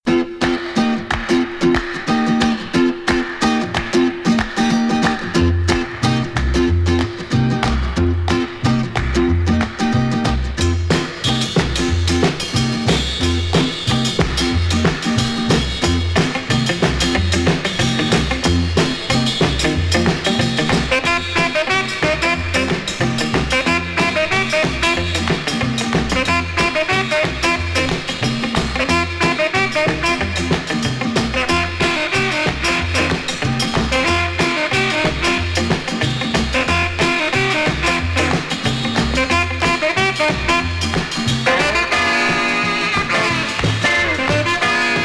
R&B, R&R, Jazz, Rock, Surf　USA　12inchレコード　33rpm　Stereo